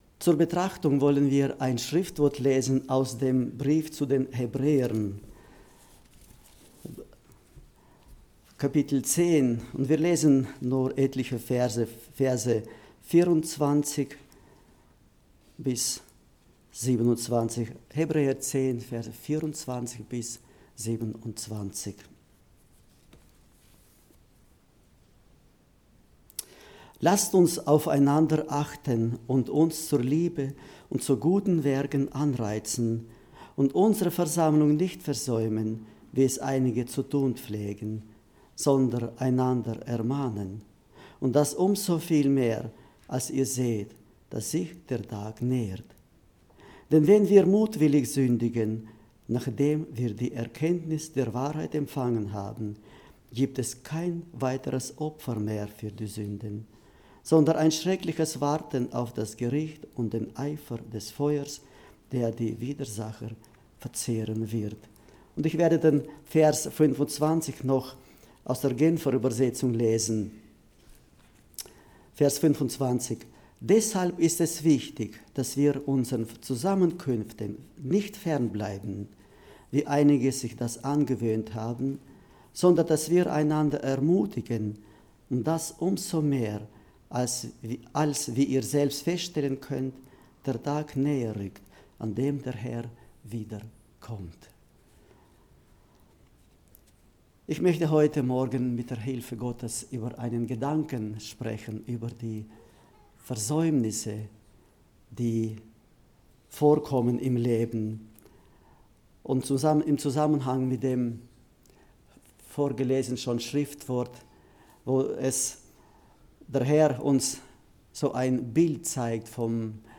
Predigten 2022 - Gemeinde Gottes Weil am Rhein